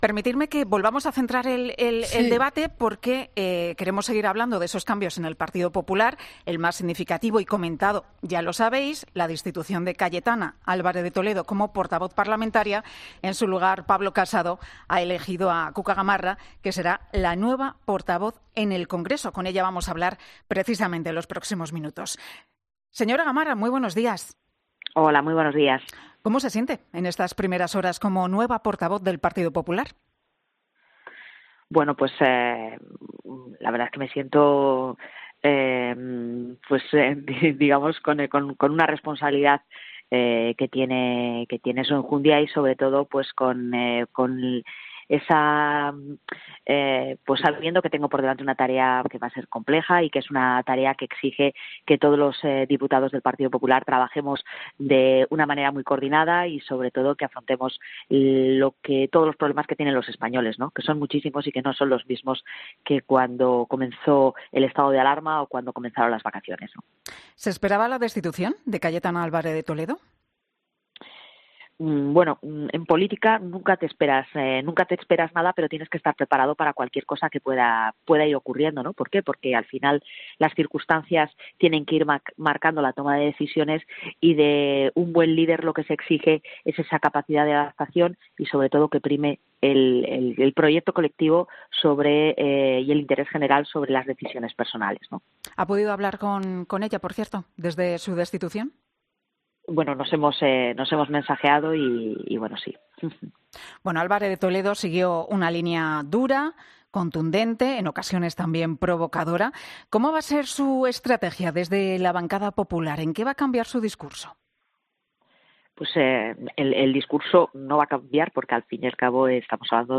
Cuca Gamarra, portavoz del Grupo Parlamentario Popular en el Congreso en 'Herrera en COPE'